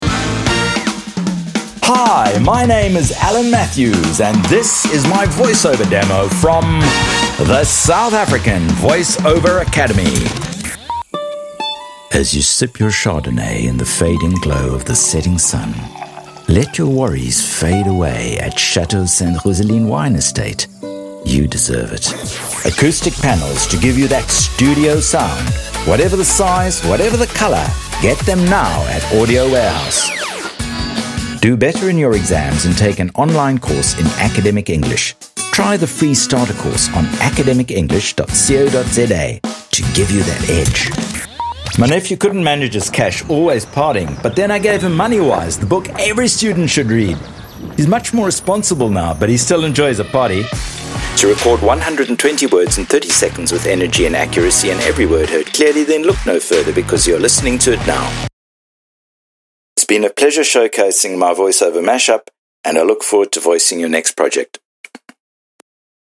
South Africa
educated, informative, intelligent, scholarly
45 - Above
My demo reels